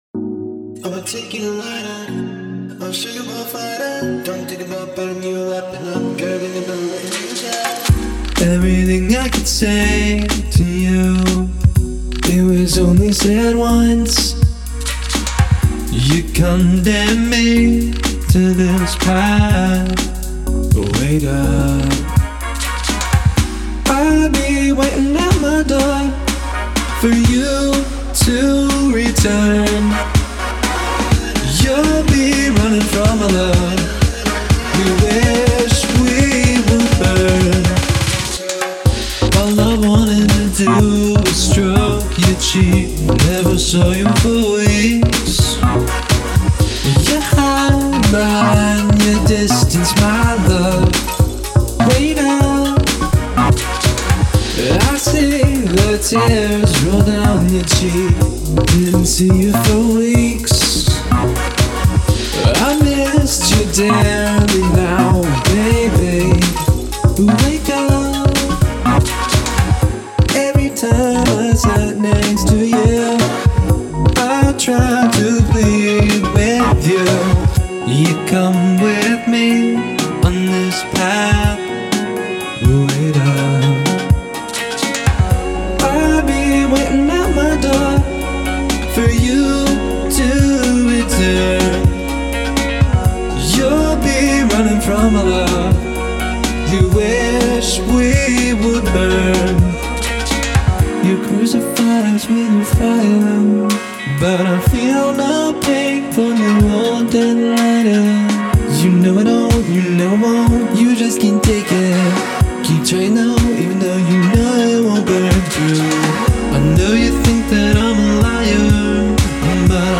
Genres Pop